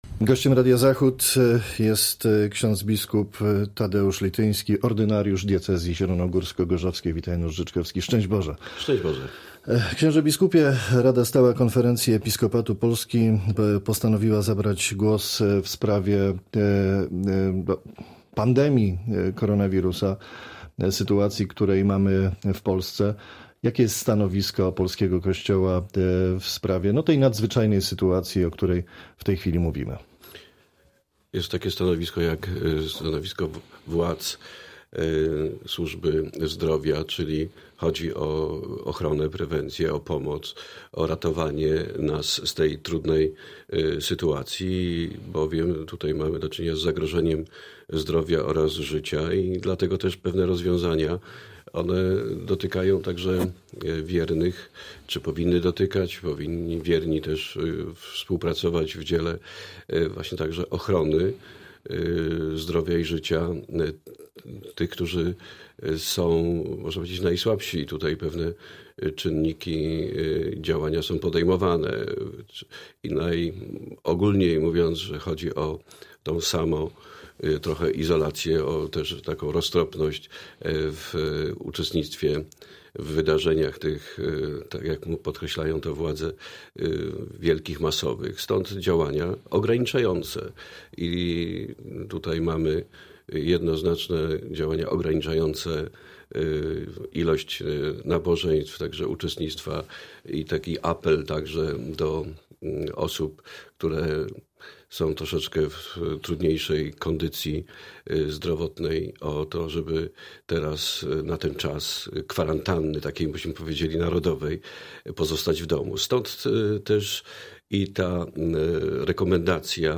poranny-litynski-cala-rozmowa.mp3